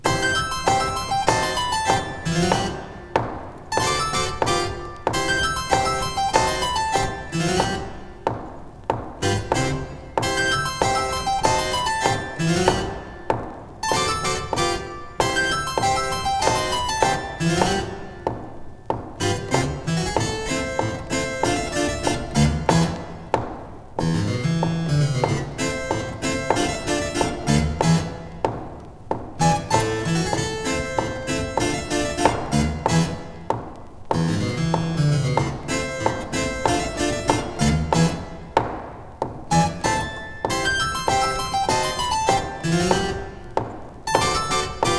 針を落とせば、遊園地にいるかのようなイメージも広がるハッピーな音楽集。
Jazz, Ragtime　USA　12inchレコード　33rpm　Stereo